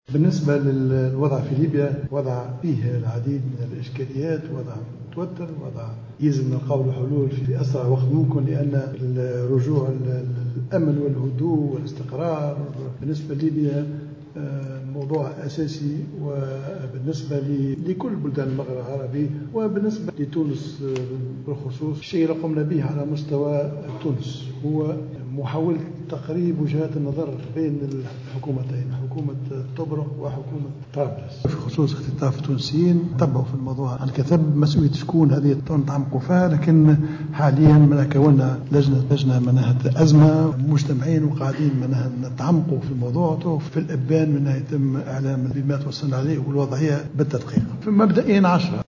وأضاف الصيد على هامش اجتماع اللجنة الكبرى المشتركة التونسية-المغربية أنه قد تم تكوين خلية أزمة لمتابعة الموضوع .